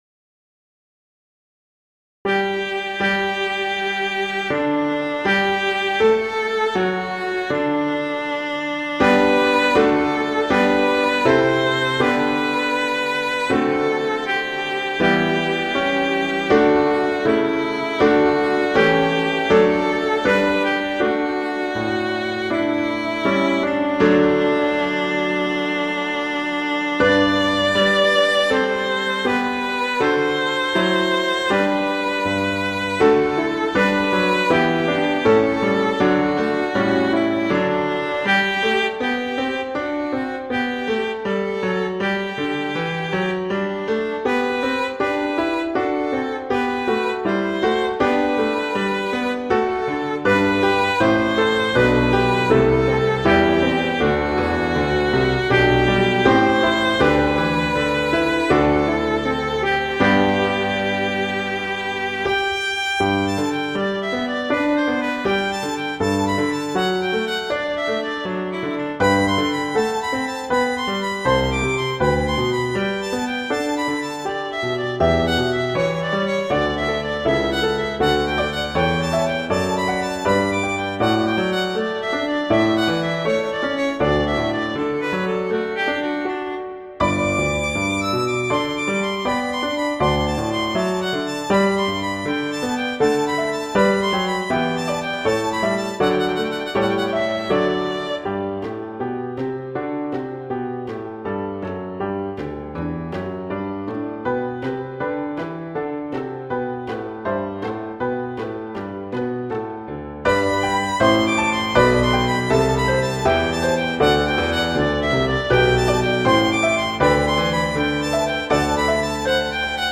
arrangements for violin and piano
violin and piano